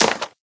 plasticstone2.ogg